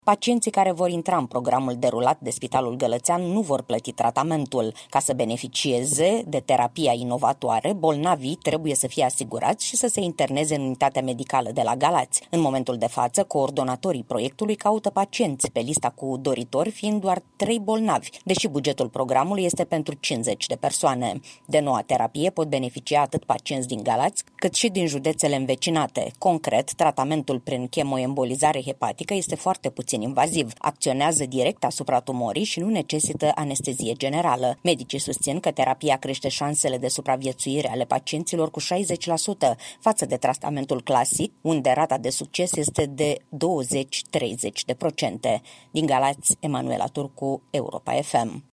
Transmite corespondenta Europa FM în Galați